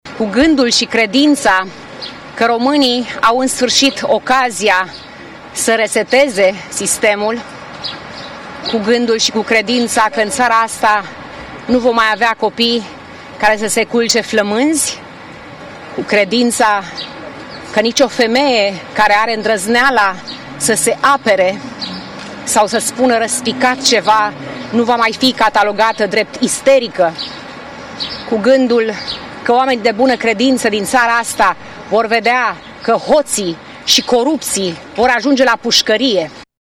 Candidata USR la prezidențiale a votat la o secție din București, alături de soțul său.